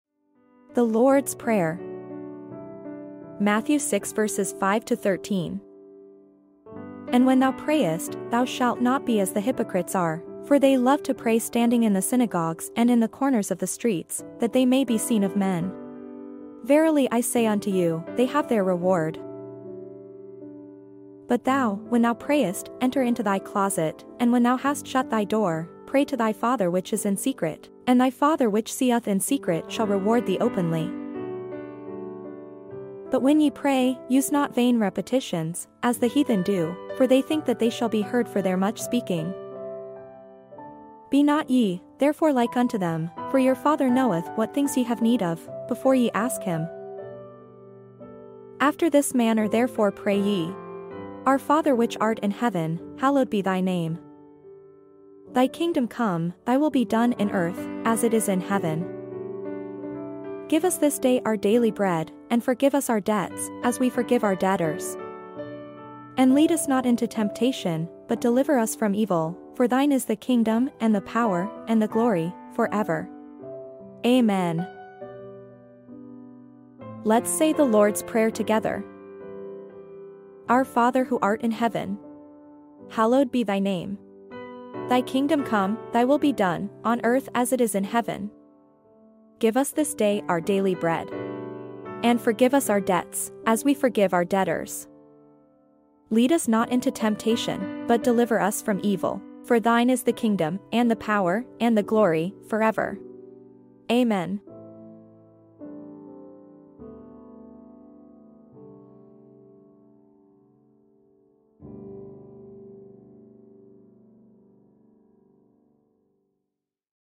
The-Lords-Prayer-Read-Aloud-Matthew-65-13-Bible-Reading.mp3